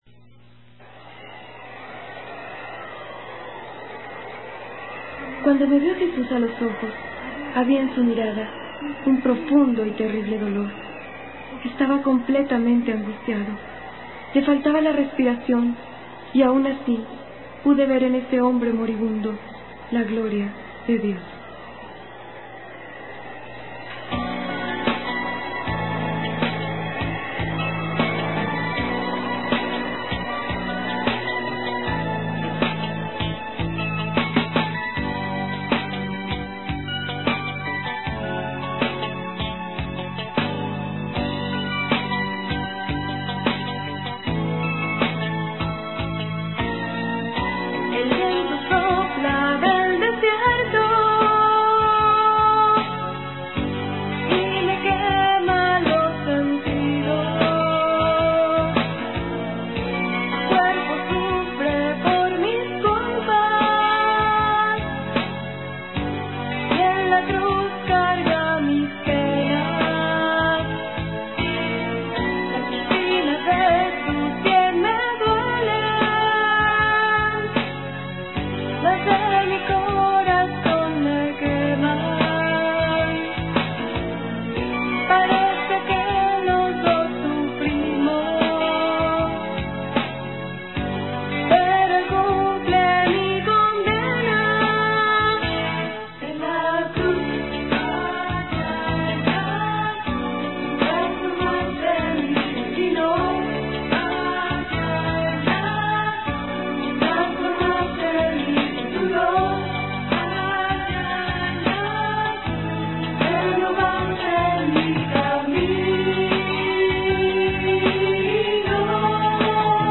(Contralto)
(Tenor)